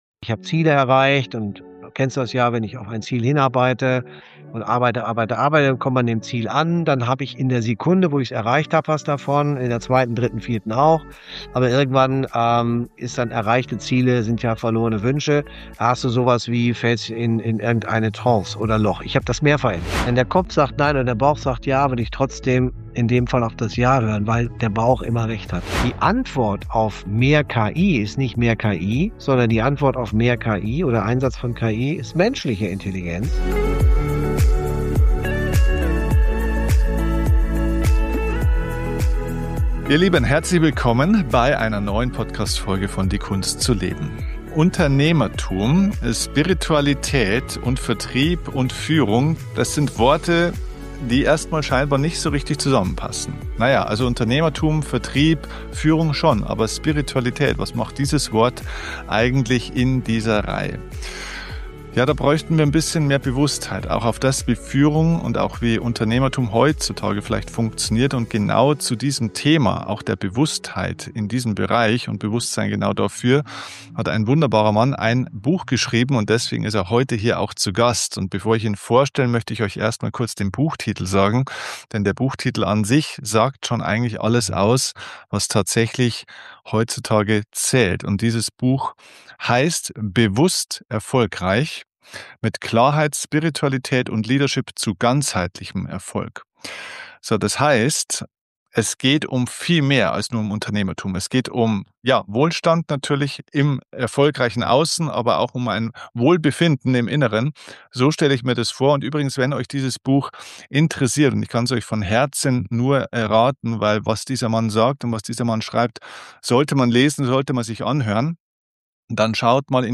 Mit Spiritualität zu ganzheitlichem Erfolg – Gespräch